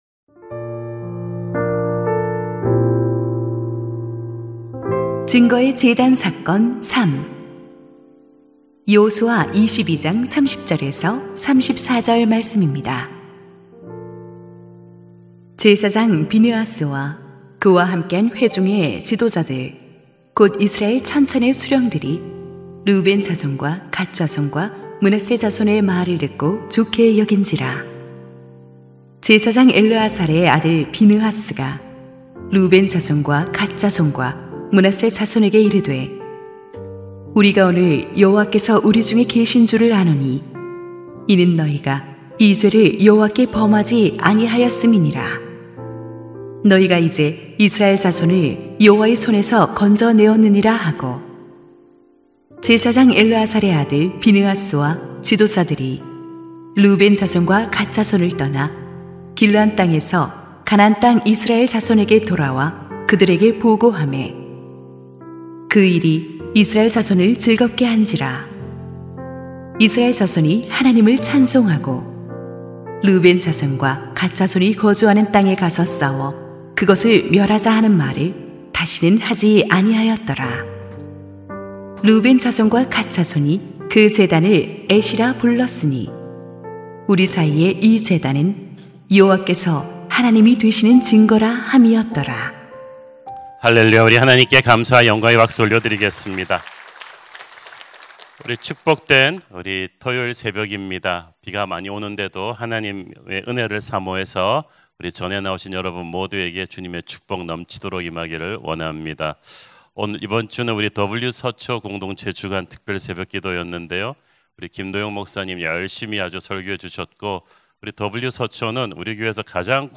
> 설교 - 새로운교회
[새벽예배]